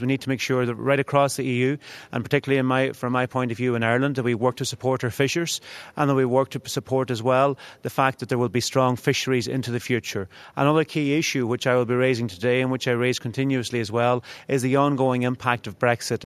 Minister for the Marine Charlie Mc Conalogue welcomes the opportunity to discuss the future of the industry: